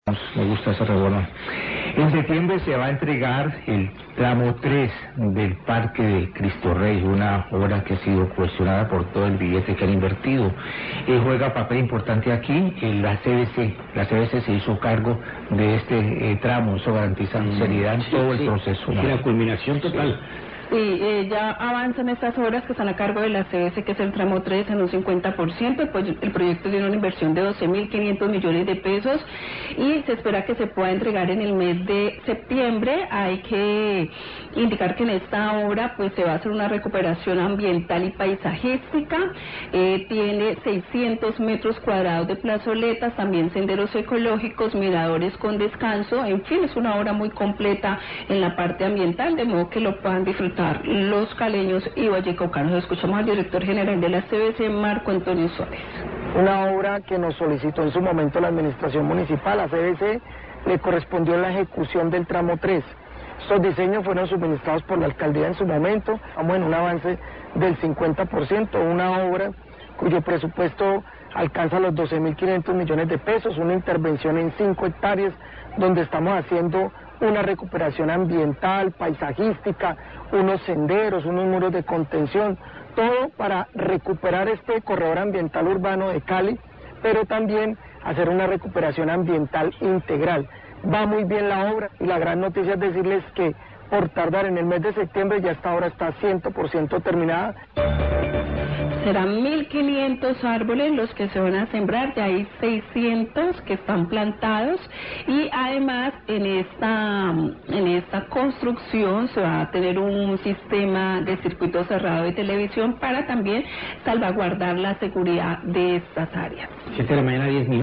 Radio
En septiembre se entregaría el tramo tres de las obras del corredor ambiental de Cristo Rey, acerca del avance de estas obras habló el director de la CVC, Marco Antonio Suárez.